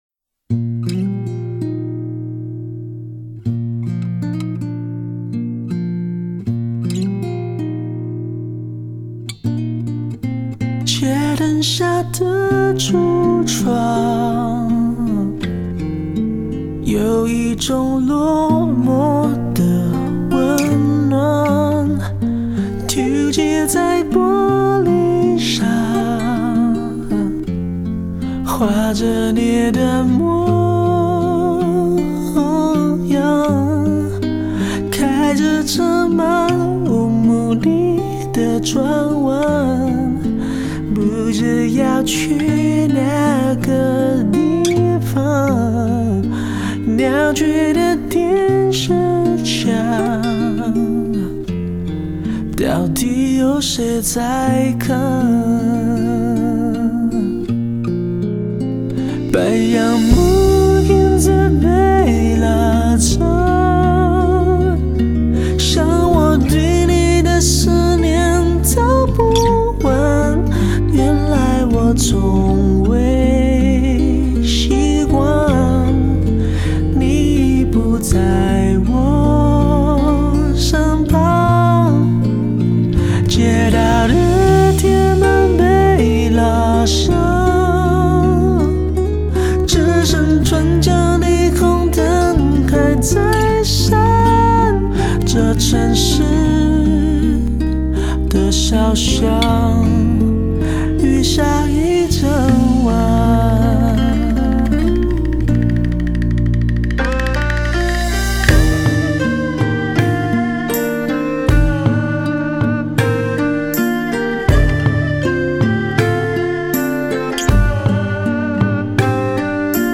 整首旋律很温和